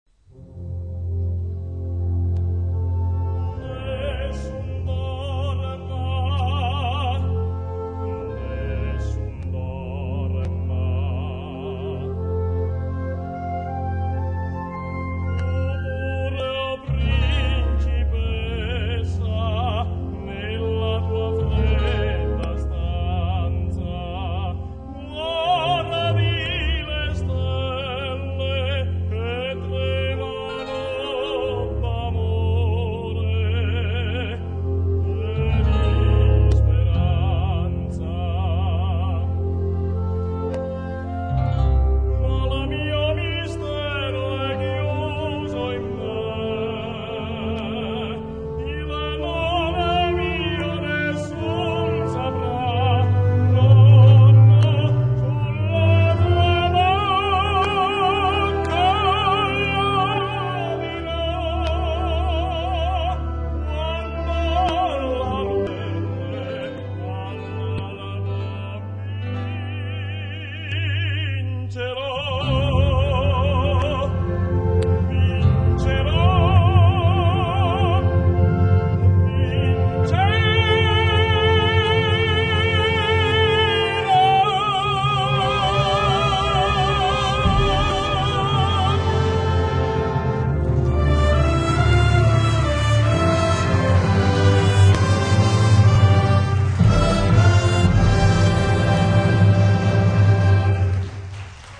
Per Tenore e Banda                       o strumento solista